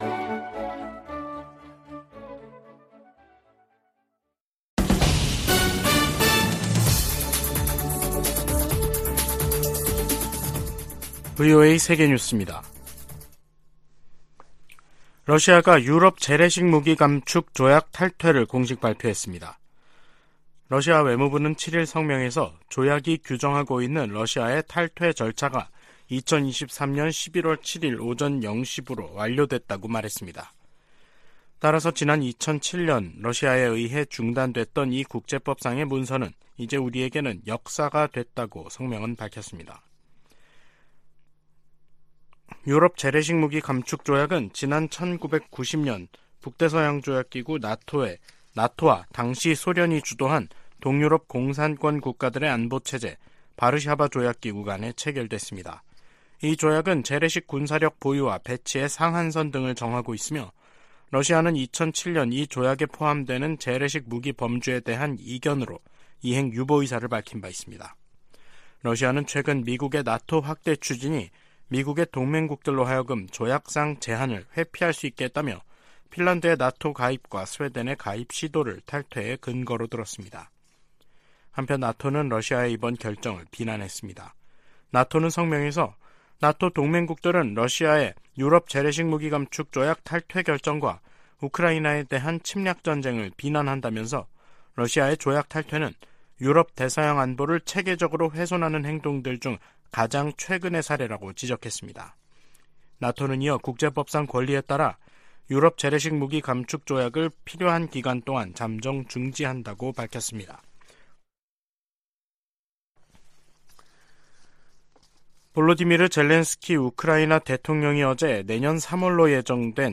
VOA 한국어 간판 뉴스 프로그램 '뉴스 투데이', 2023년 11월 7일 3부 방송입니다. 미 국방부는 북한이 미한일 군사 협력에 연일 비난과 위협을 가하는데 대해, 미국은 이들 정부와 계속 협력해 나가겠다는 뜻을 거듭 밝혔습니다. 유럽과 중동의 두 개 전쟁이 한반도를 비롯한 아시아 안보도 위협하고 있다고 미 상원의원들이 밝혔습니다. 한국과 일본이 이달말 한중일 외교장관회담에서 북러 밀착에 대한 중국의 불안을 공략해야 한다고 미 전문가들이 말했습니다.